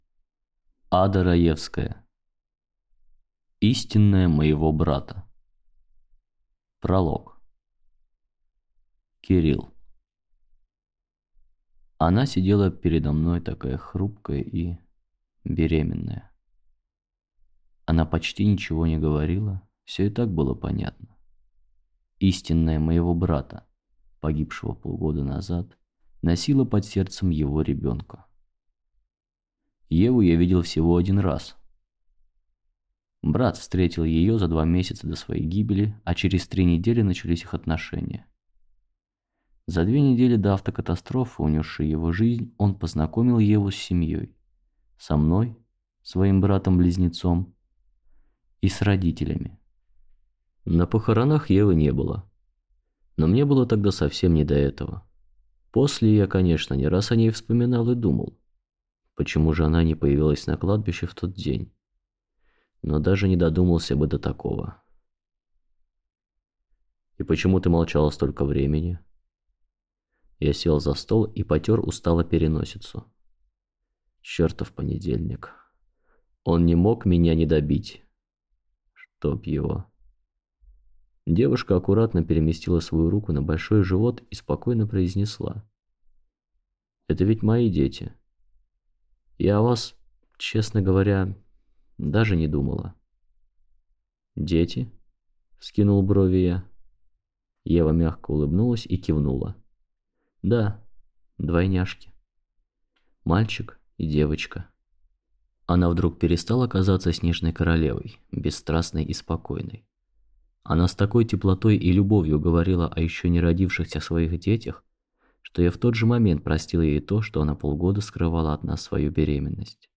Аудиокнига Истинная моего брата | Библиотека аудиокниг